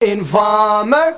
Amiga 8-bit Sampled Voice
1 channel
samplerdemo.mp3